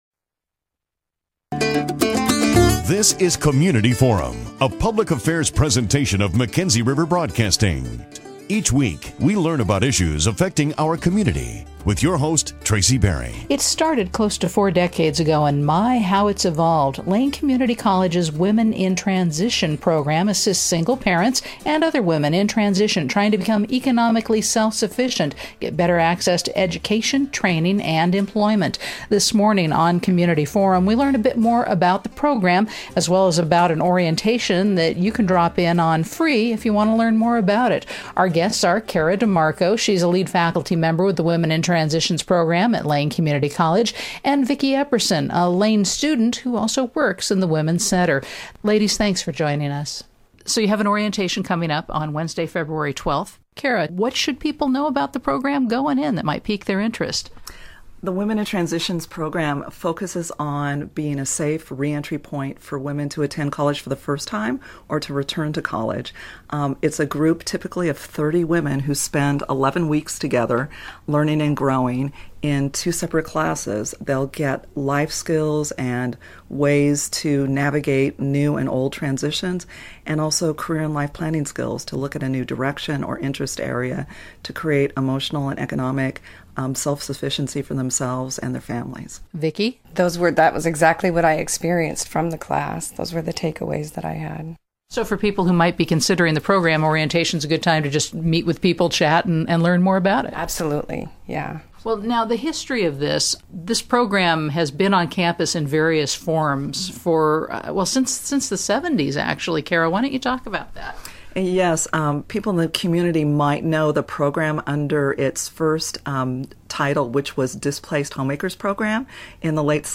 Listen to this community forum interview and learn what it means to be a Women in Transition graduate!